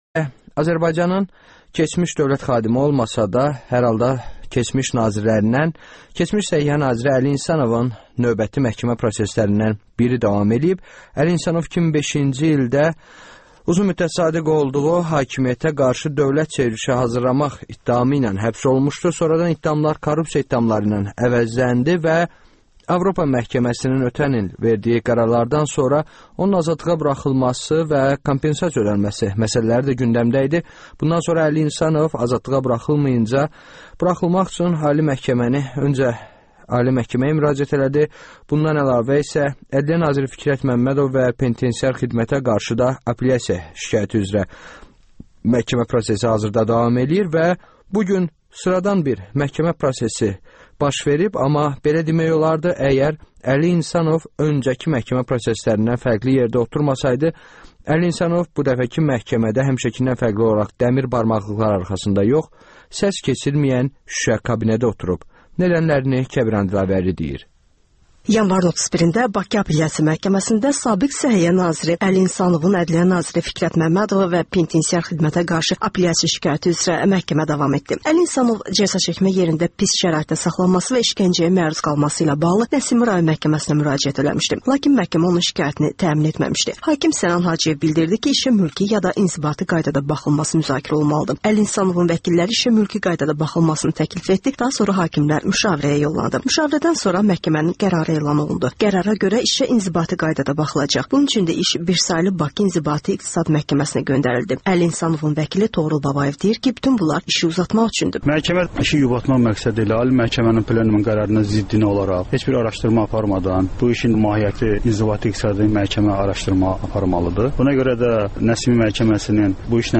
Əli İnsanovun məhkəməsindən reportaj